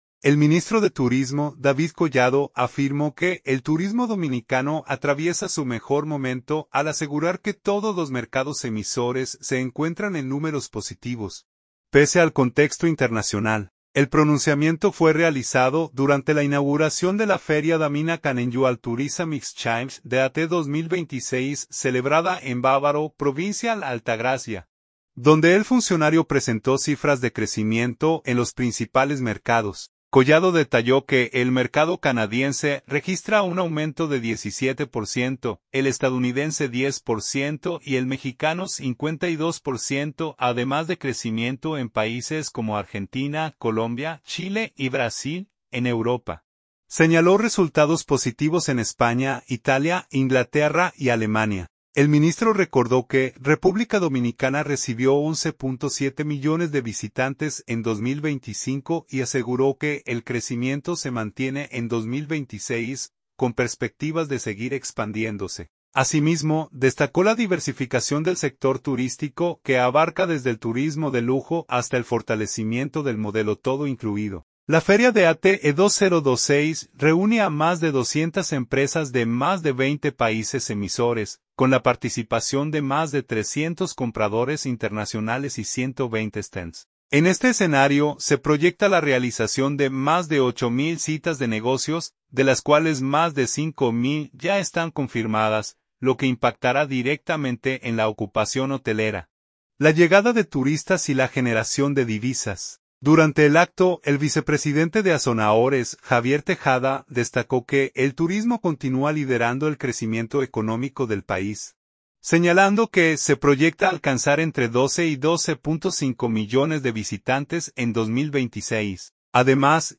El pronunciamiento fue realizado durante la inauguración de la feria Dominican Annual Tourism Exchange (DATE) 2026, celebrada en Bávaro, provincia La Altagracia, donde el funcionario presentó cifras de crecimiento en los principales mercados.